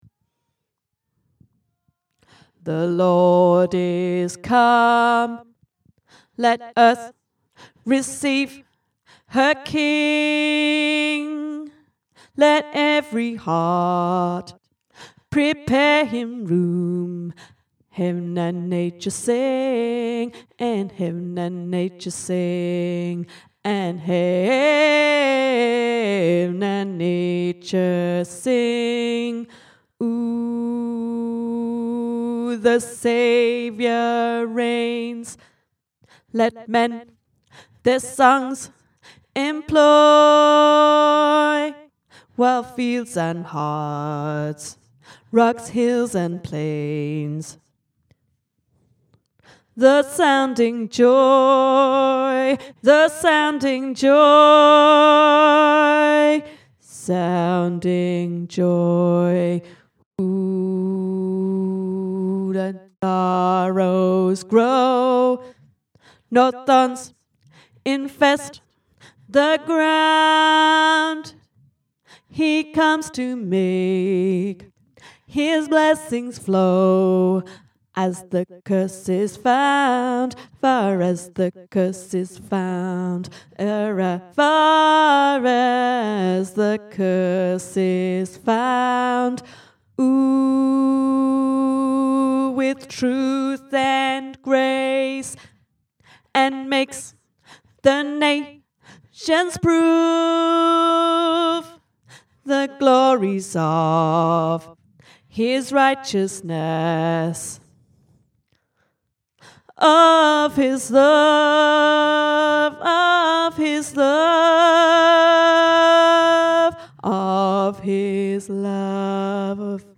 Joy-to-the-World-Tenor.mp3